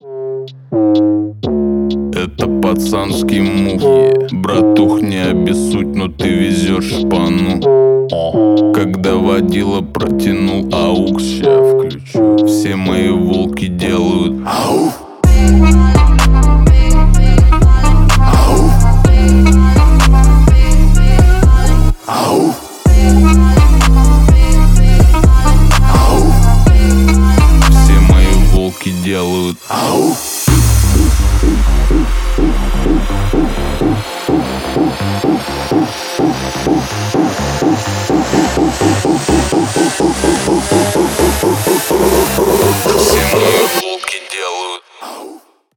• Качество: 320 kbps, Stereo
Ремикс
Рэп и Хип Хоп